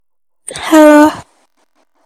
Suara Wanita Halo
Kategori: Suara manusia
Keterangan: Download suara Wanita Halo mp3 dengan suara imut cocok untuk nada dering ponsel, notifikasi WA, dan editing video.
suara-wanita-halo-id-www_tiengdong_com.mp3